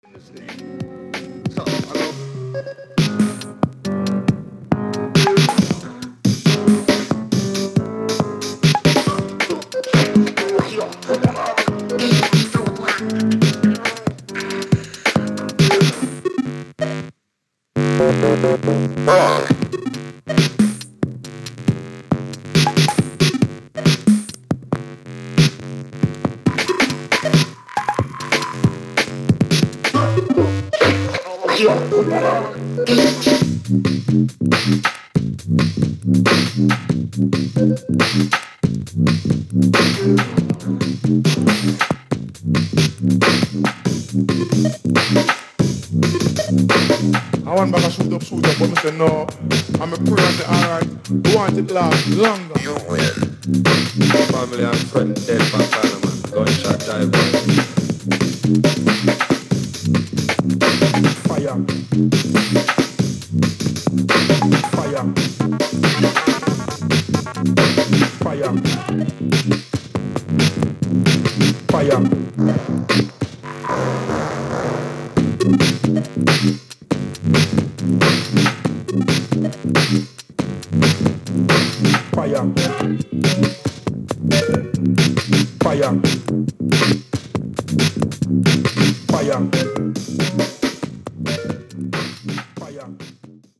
トリッキーなリズムにハマります（笑）